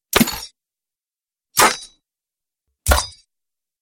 На этой странице собраны звуки копья: удары, скрежет, броски и другие эффекты.
Звуки копья: три удара о броню